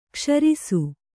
♪ kṣarisu